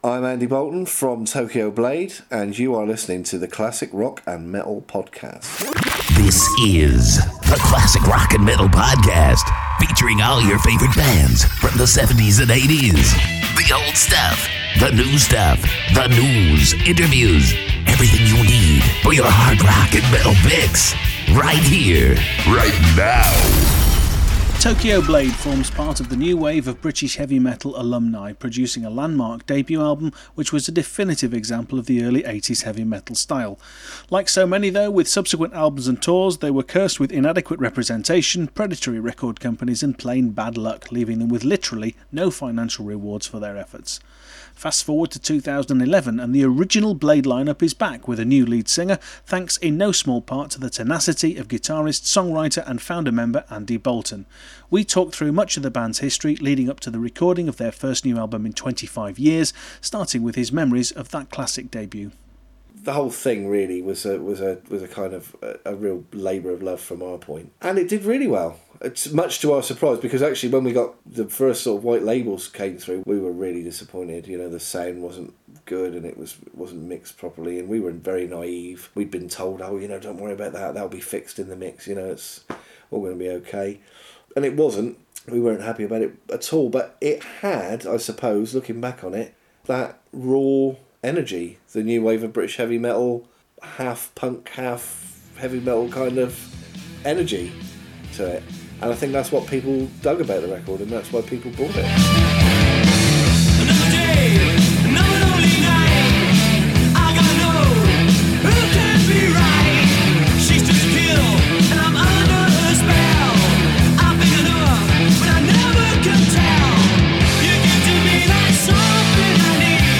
Tokyo Blade Interview